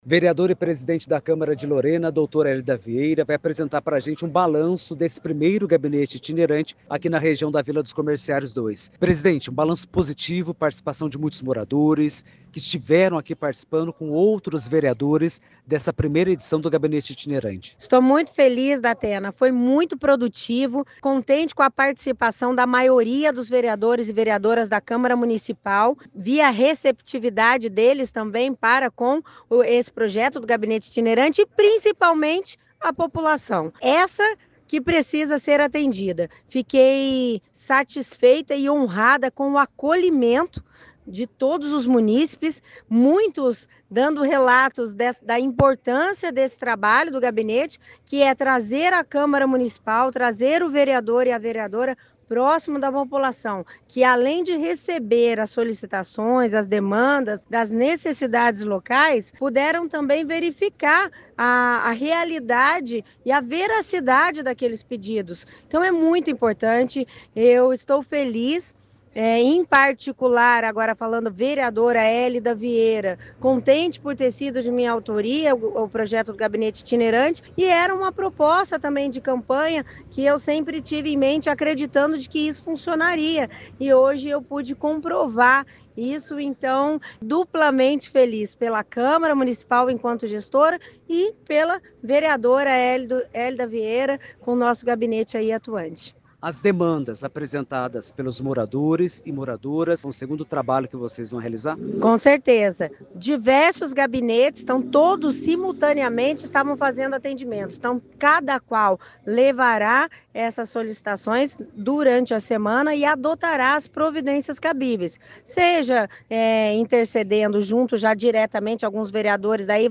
Áudio da vereadora e presidente da Câmara, Dra. Élida Vieira (PODE);